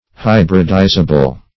hybridizable - definition of hybridizable - synonyms, pronunciation, spelling from Free Dictionary
Hybridizable \Hy"brid*i`za*ble\, a.